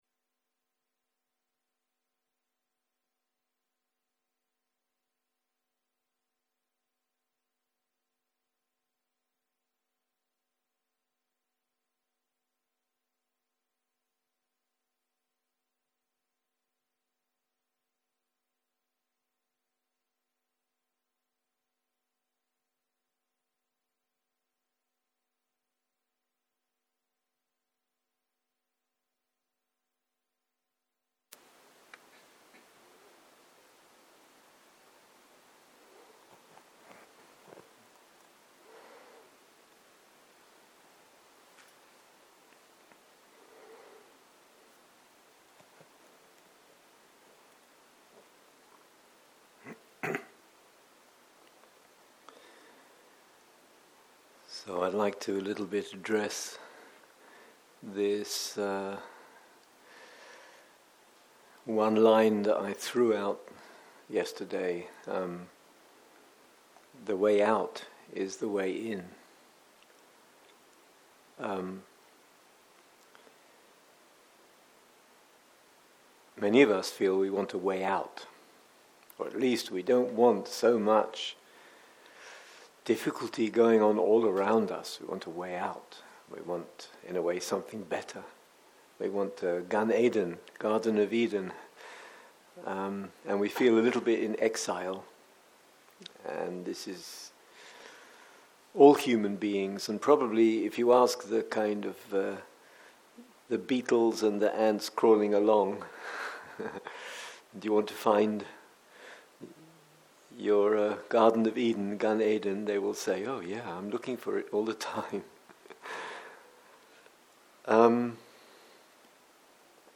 שיחת דהרמה
סוג ההקלטה: שיחות דהרמה